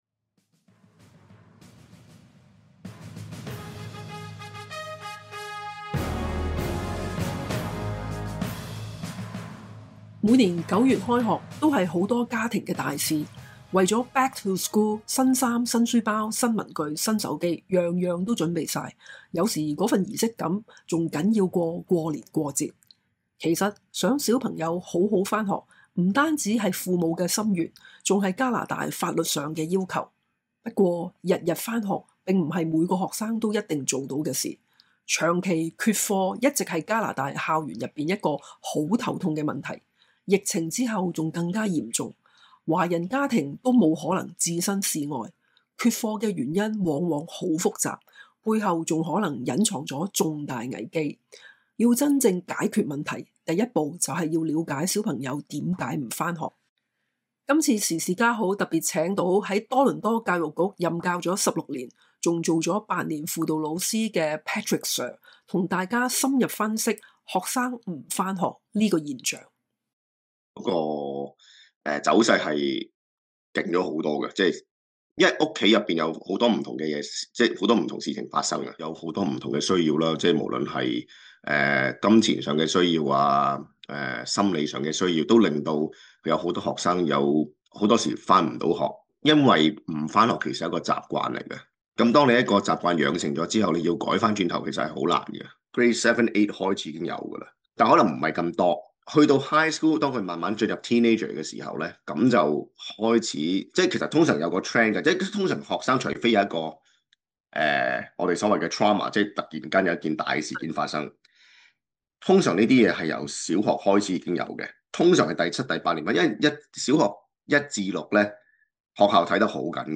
孩子為何不上課？資深教師剖析緣由孩子為何不上課？資深教師剖析緣由